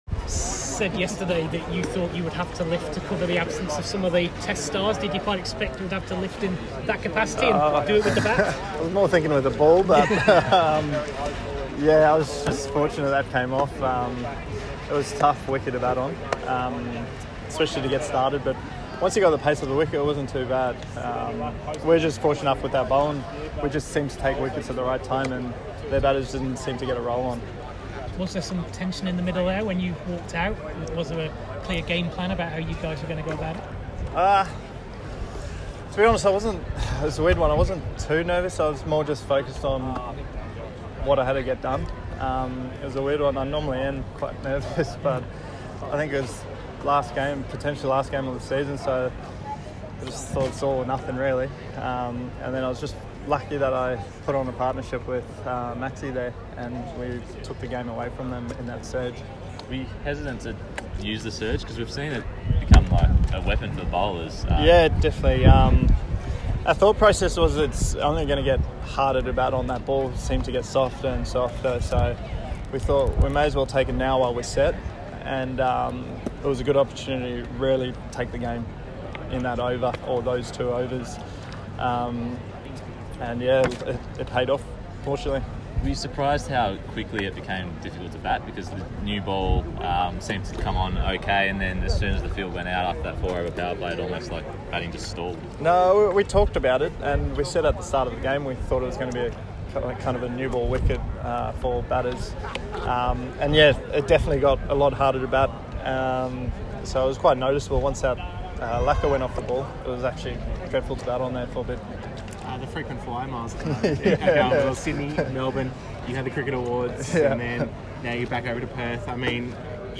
Michael Neser spoke to media tonight after his player of the match performance ln tonight’s Challenger Final against the Sydney Sixers.